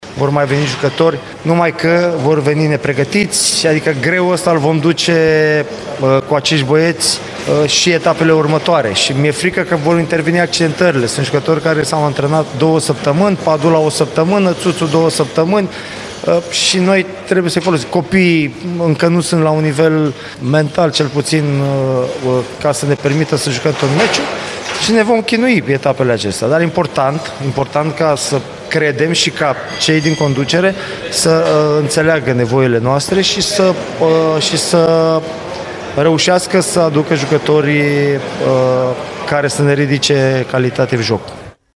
Antrenorul UTA-ei, Adrian Mihalcea, convins că și perioada următoare va fi dificilă: